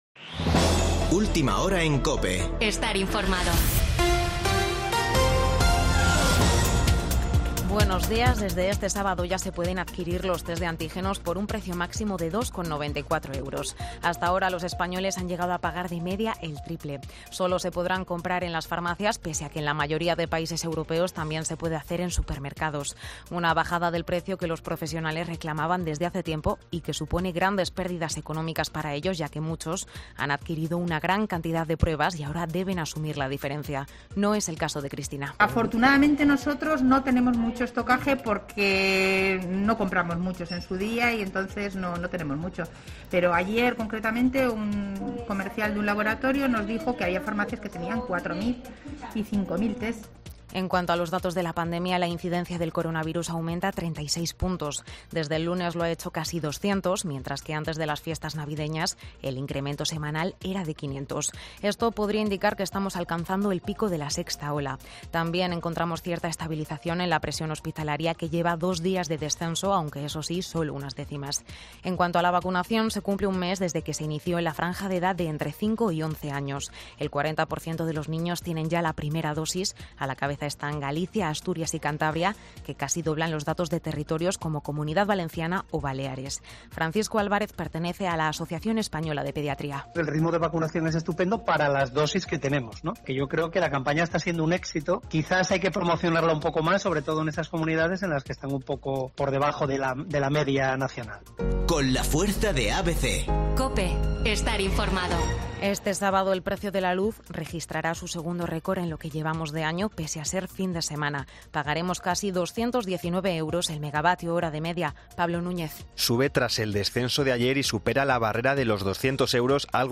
Boletín de noticias COPE del 15 de enero de 2022 a las 09.00 horas